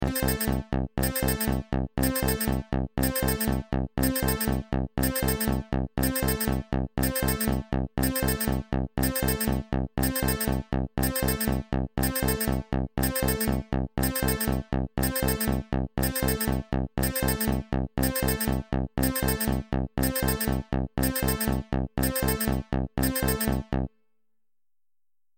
دانلود آهنگ هشدار 17 از افکت صوتی اشیاء
دانلود صدای هشدار 17 از ساعد نیوز با لینک مستقیم و کیفیت بالا
جلوه های صوتی